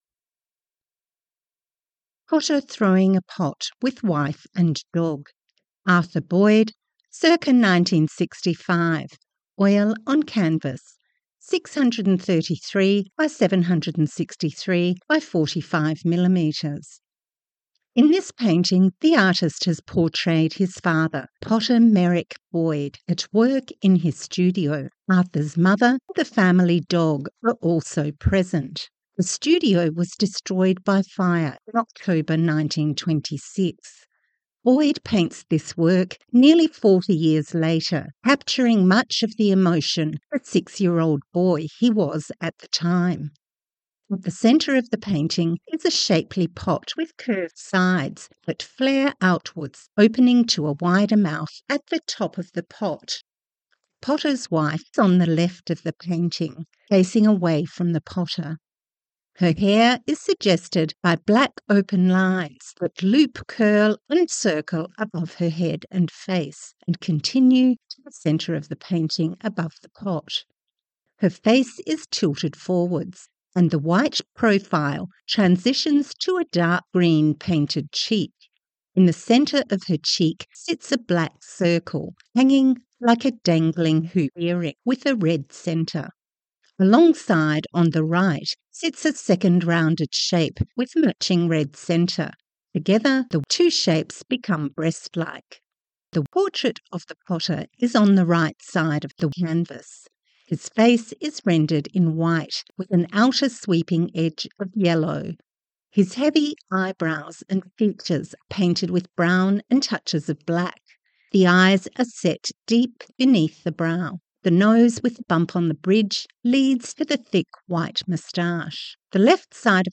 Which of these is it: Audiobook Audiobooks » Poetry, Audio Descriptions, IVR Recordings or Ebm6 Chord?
Audio Descriptions